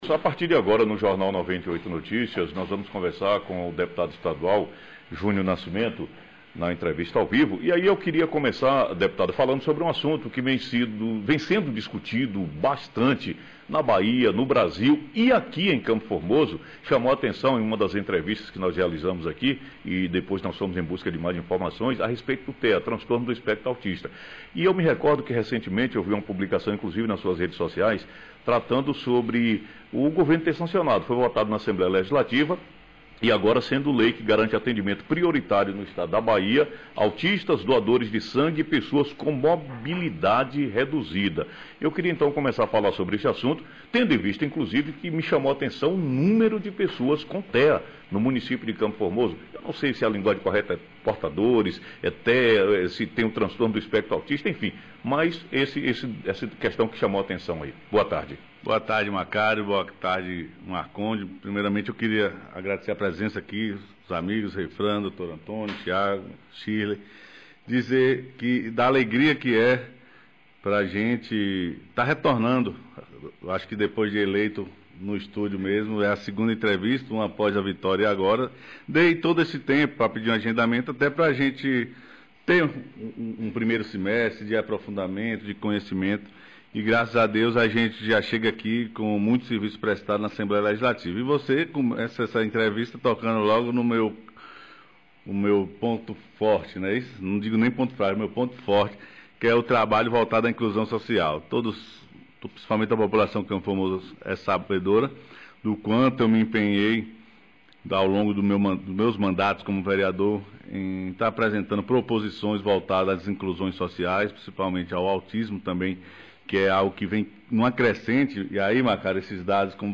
Entrevista Dep. Estadual Júnior Nascimento